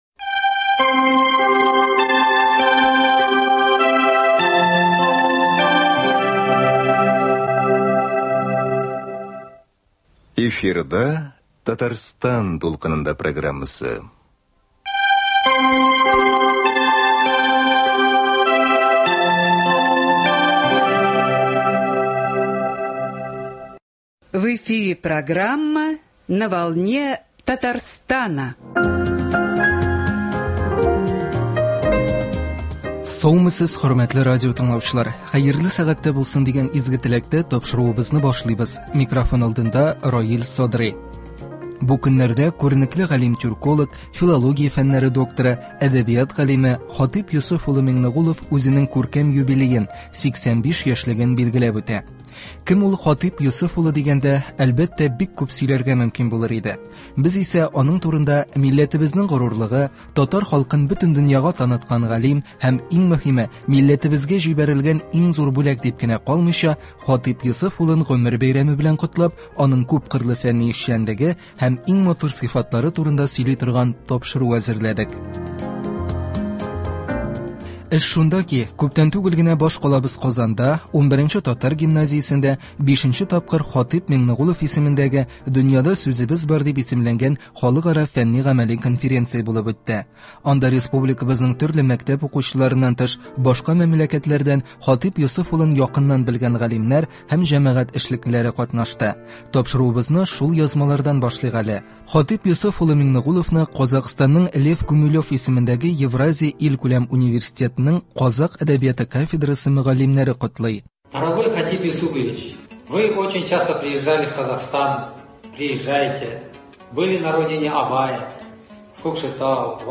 Әдәбият галиме Х.Миңнегулов исемендәге фәнни-гамәли конференциядән репортаж.